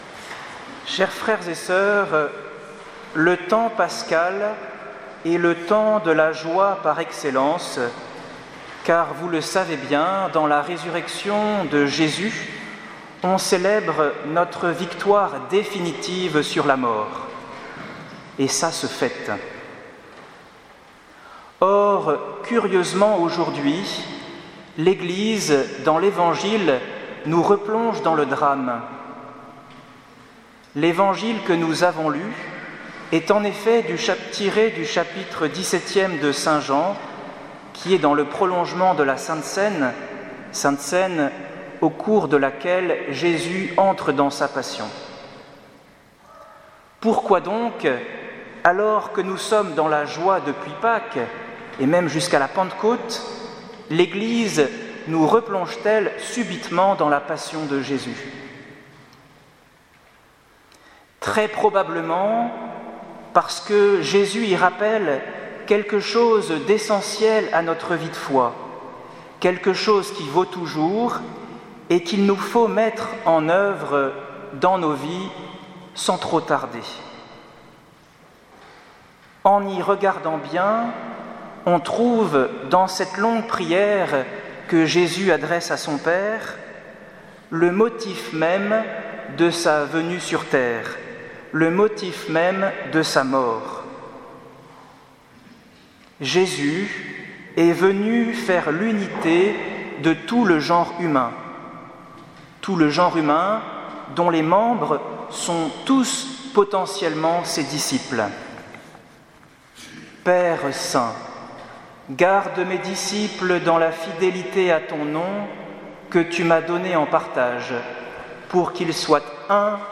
Homélie du 7ème dimanche du Temps Pascal 2018